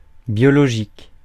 Ääntäminen
Synonyymit naturel Ääntäminen France: IPA: /bjɔ.lɔ.ʒik/ Haettu sana löytyi näillä lähdekielillä: ranska Käännös Ääninäyte Adjektiivit 1. biological 2. organic US 3. biologic Suku: f .